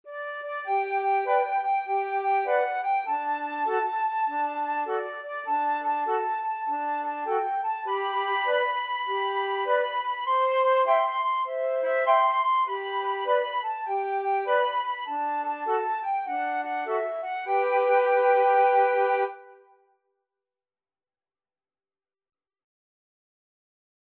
Childrens Childrens Flute Trio Sheet Music If You're Happy And You Know It
Free Sheet music for Flute Trio
4/4 (View more 4/4 Music)
G major (Sounding Pitch) (View more G major Music for Flute Trio )
With a swing!